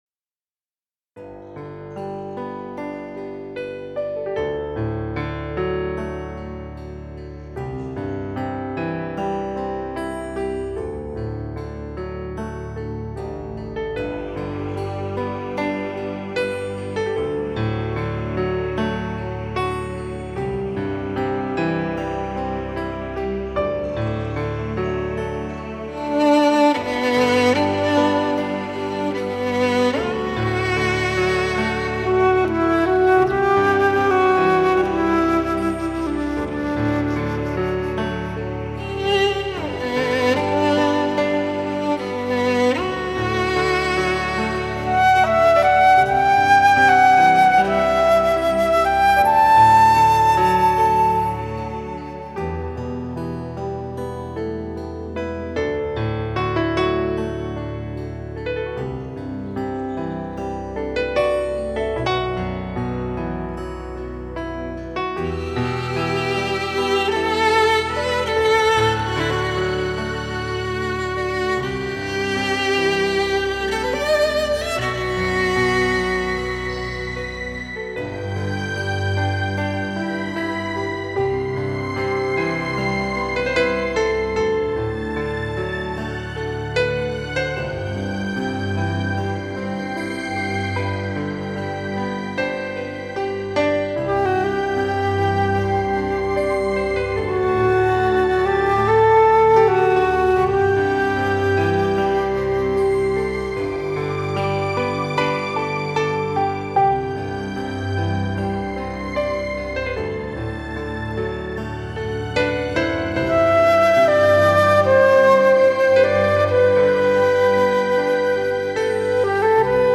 3周前 纯音乐 5